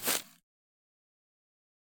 footsteps-single-outdoors-002-05.ogg